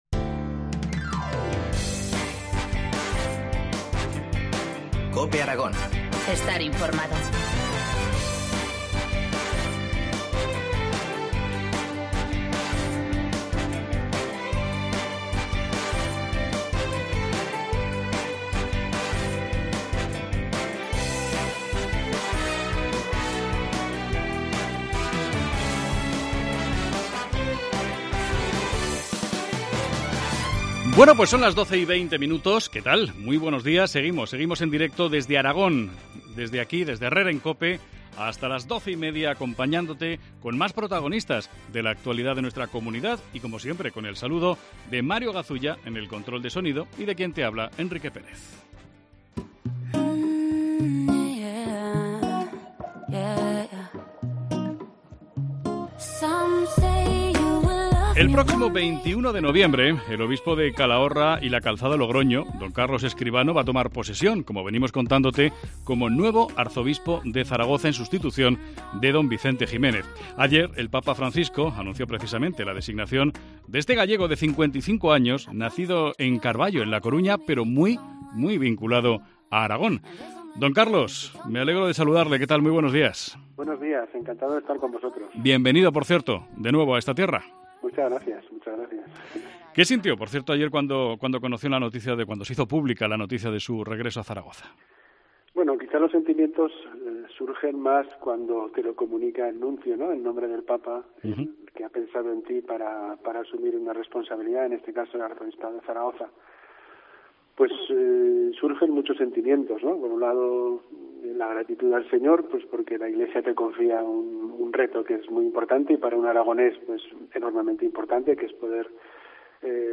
Entrevista a D. Carlos Escribano, nuevo arzobispo de Zaragoza.